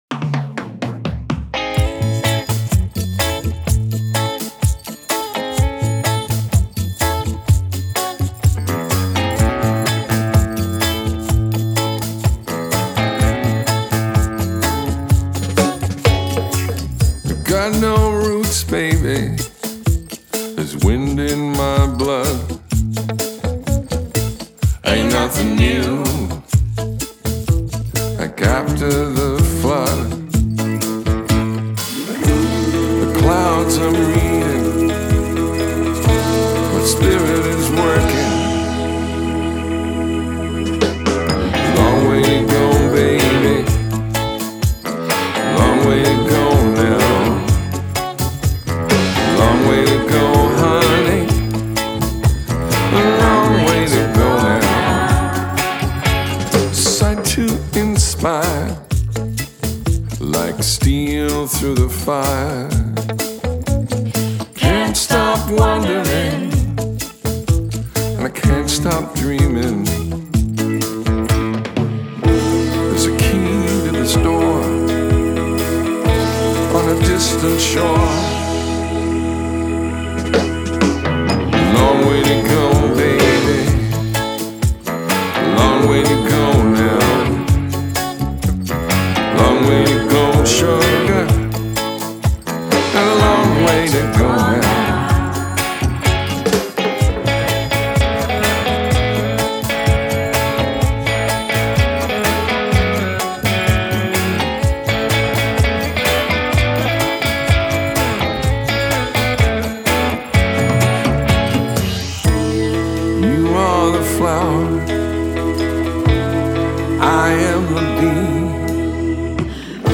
vocals, electric guitars
bass
drums, percussion, keyboards, backing vocals